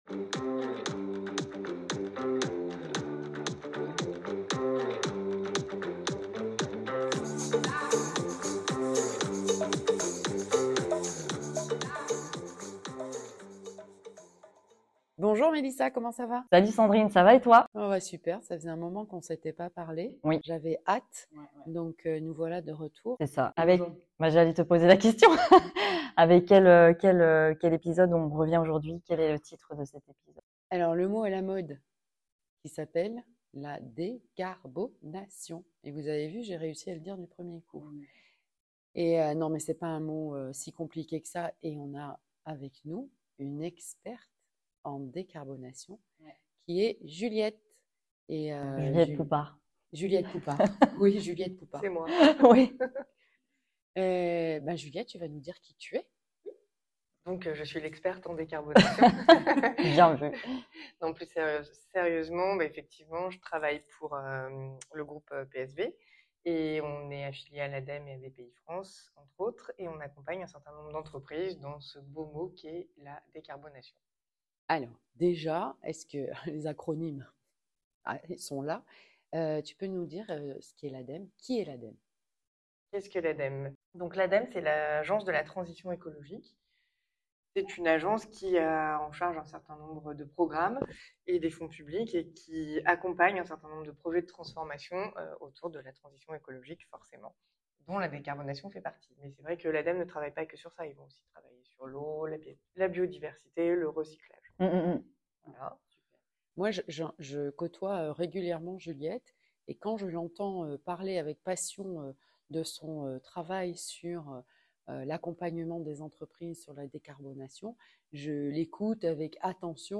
Préparez-vous à une conversation éclairante, pleine de solutions et d'inspiration pour un avenir plus durable !